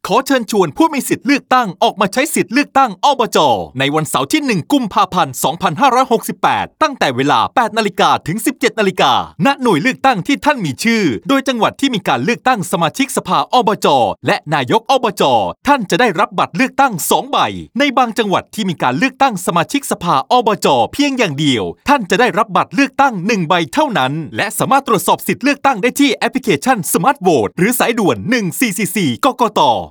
สปอตวิทยุเชิญชวนไปใช้สิทธิเลือกตั้ง ส.อบจ. และนายก อบจ. ผ่านทางสื่อวิทยุ ณ จุดขายในร้านสะดวกซื้อ